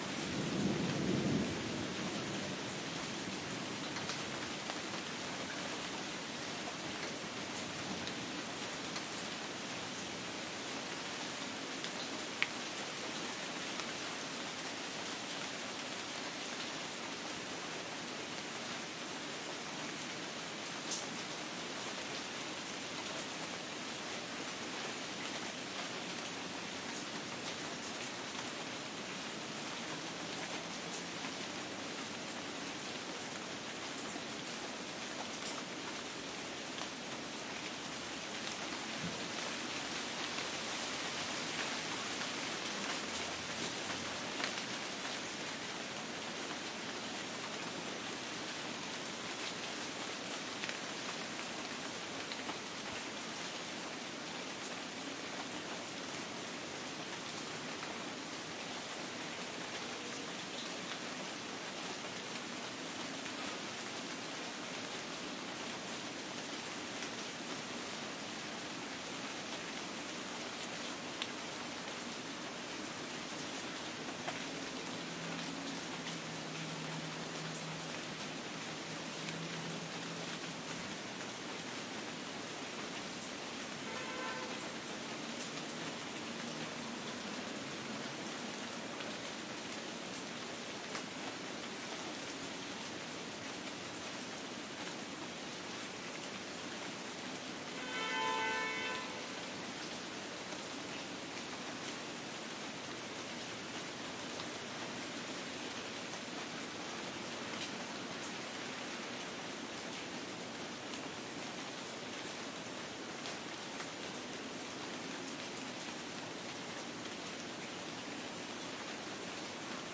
标签： 门廊 风暴
声道立体声